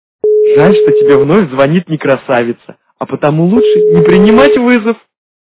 При прослушивании Мужской голос - Жаль, что тебе опять звонит не красавица... качество понижено и присутствуют гудки.
Звук Мужской голос - Жаль, что тебе опять звонит не красавица...